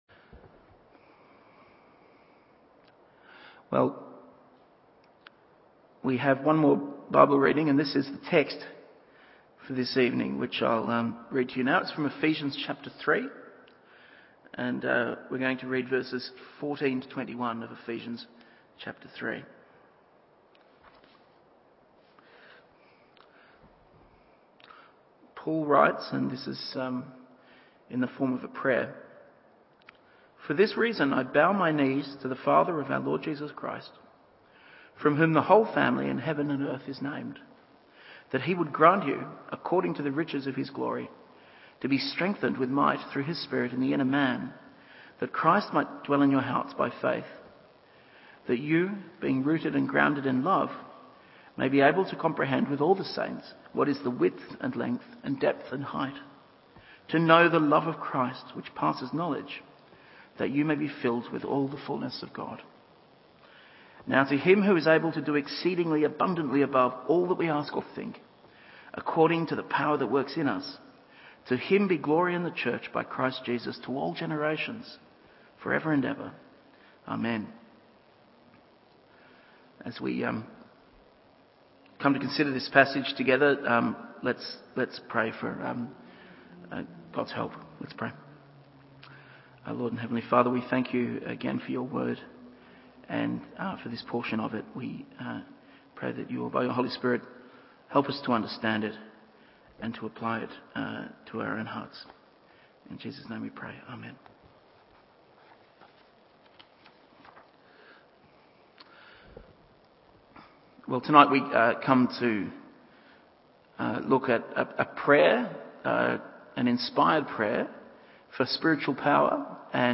Evening Service Ephesian 3:14-21…